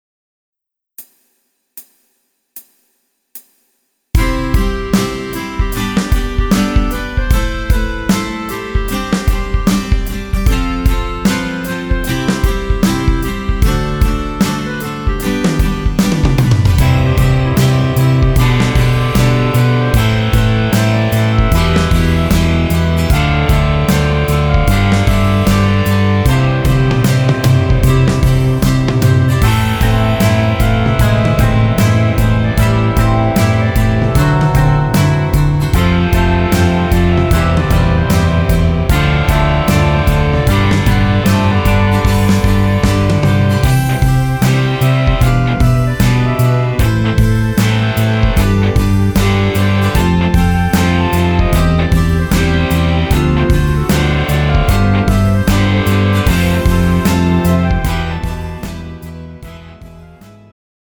음정 남자키
장르 축가 구분 Pro MR